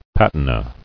[pat·i·na]